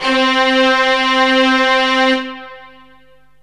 STRINGS 0004.wav